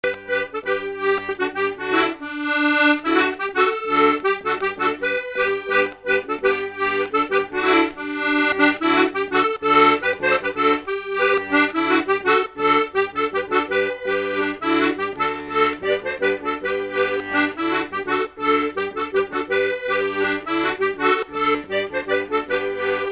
Chants brefs - A danser
Résumé instrumental
danse : scottish (autres)
Pièce musicale inédite